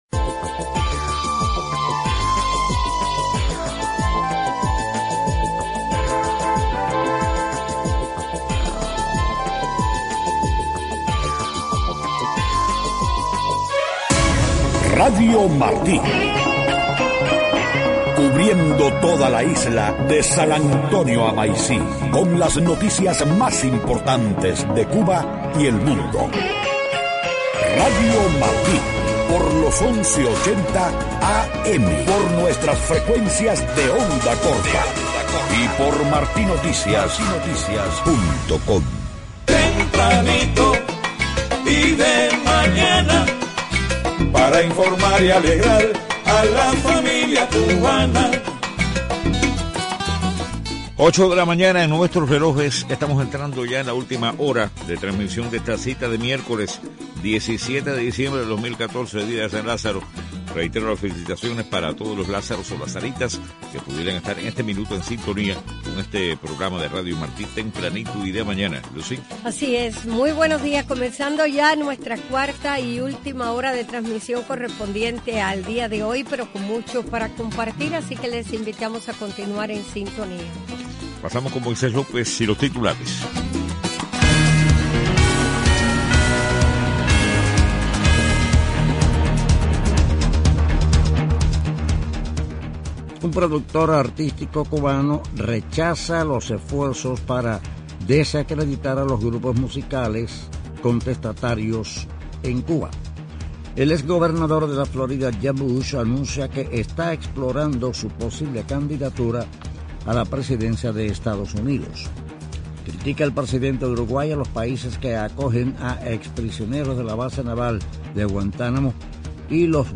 8:00 a.m. Noticias: Productor artístico cubano rechaza esfuerzos para desacreditar a los grupos musicales contestatarios de la isla. Ex gobernador de la Florida, Jeb Bush, anuncia proceso exploratorio para posible candidatura del Partido Republicano por la Presidencia de EEUU en 2016. Presidente uruguayo critica a países que acogen a ex prisioneros de Guantánamo y los mantienen vigilados.